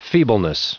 Prononciation du mot : feebleness